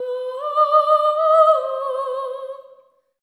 LEGATO 06 -L.wav